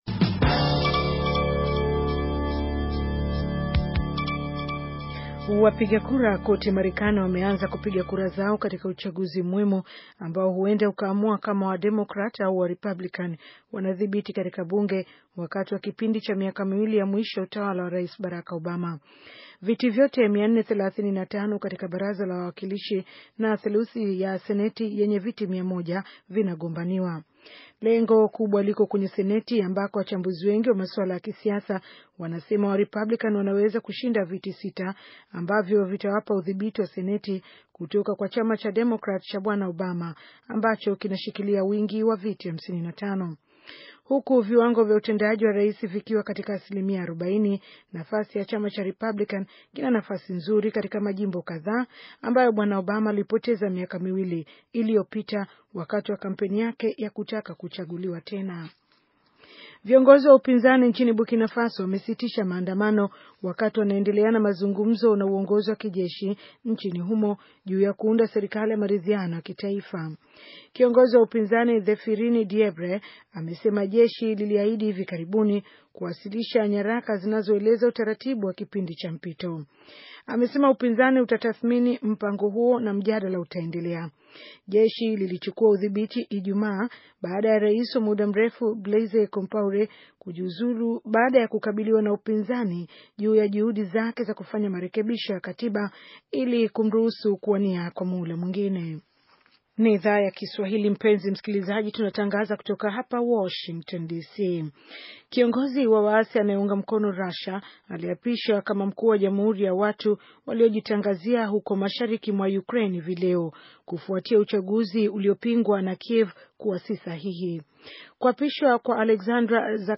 Taarifa ya habari - 6:00